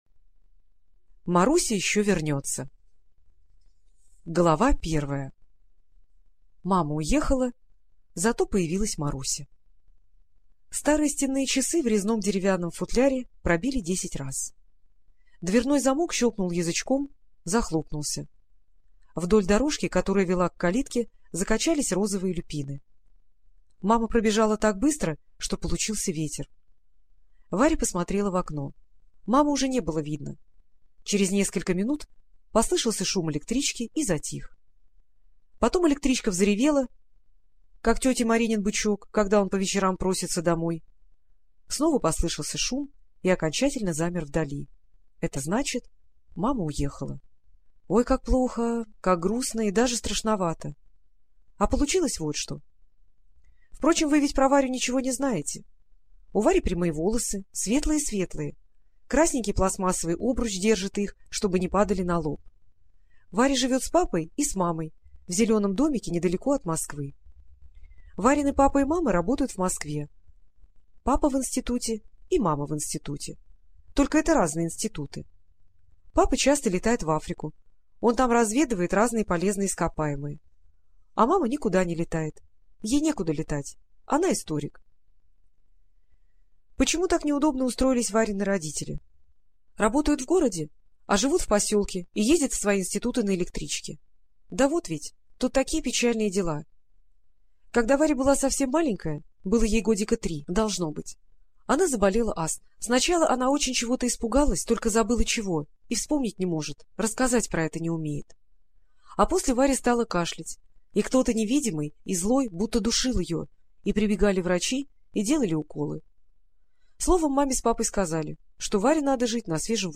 Маруся еще вернется - аудиосказка Токмаковой И.П. Сказка о том, как девочка Варя отправляется в путешествие чтобы спасти сказочную страну.